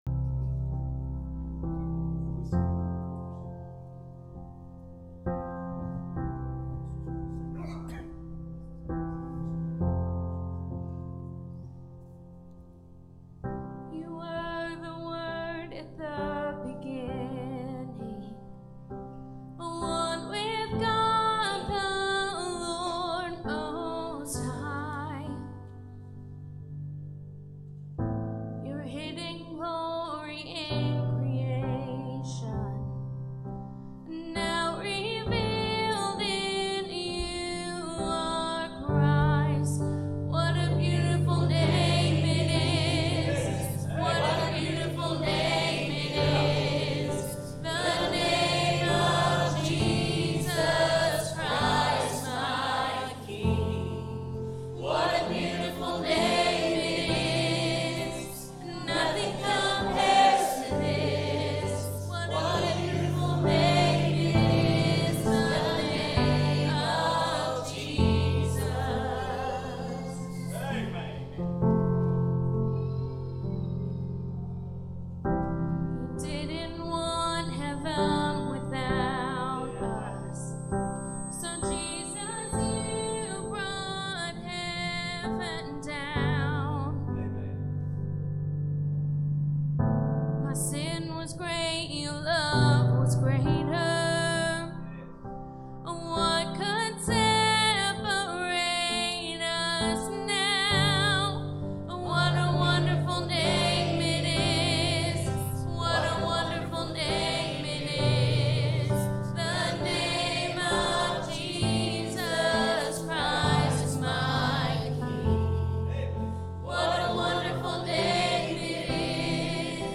Sermons | Richardson's Cove Baptist Church